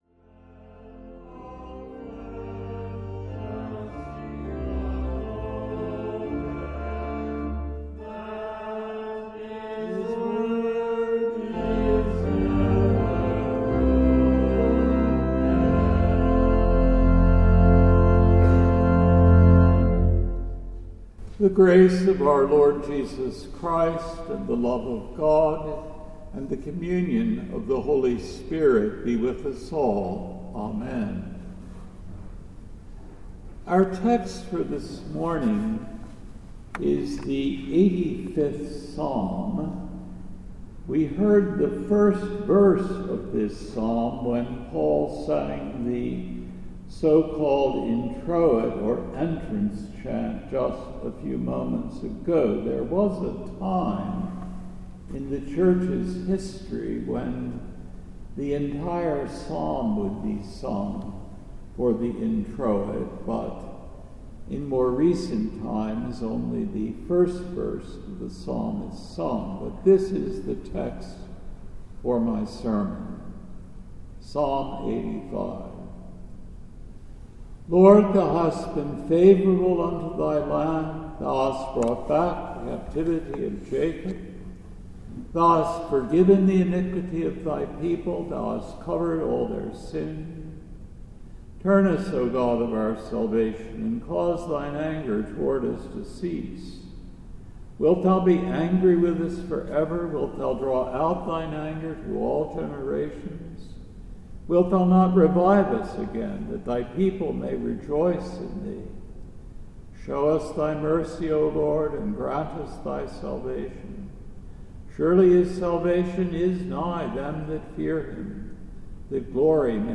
The Third Sunday in Advent December 15, 2024 AD